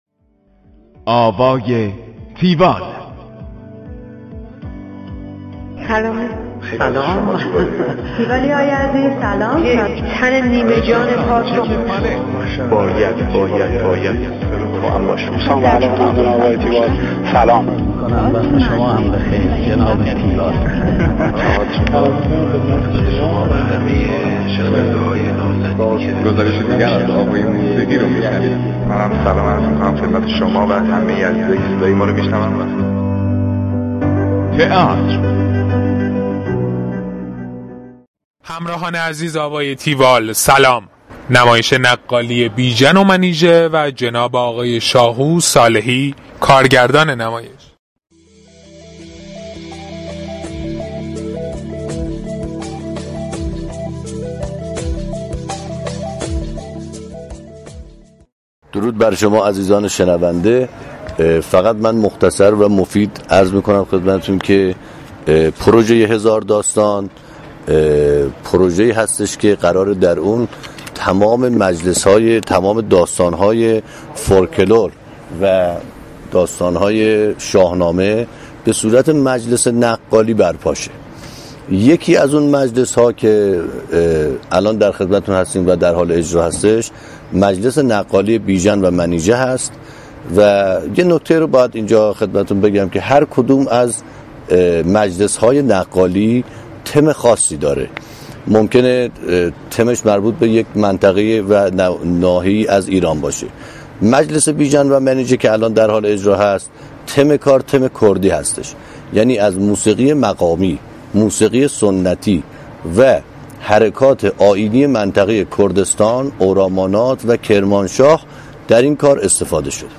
گفتگوی تیوال با